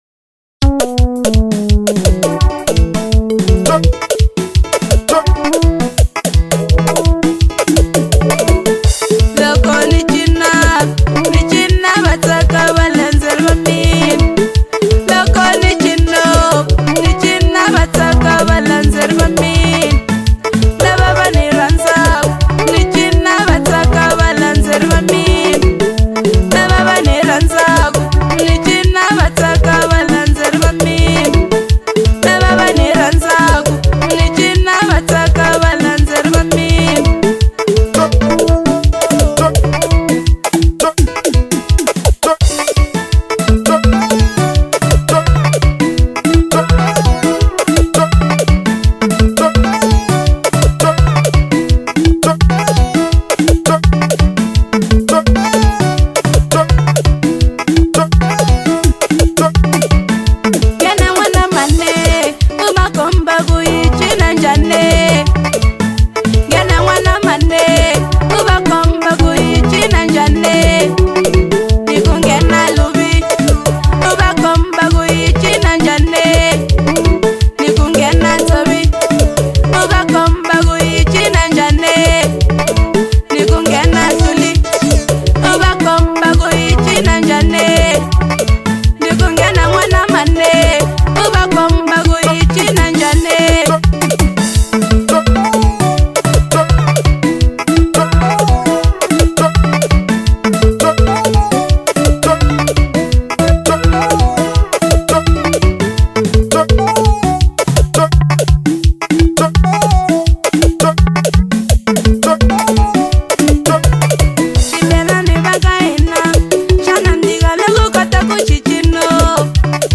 03:51 Genre : Xitsonga Size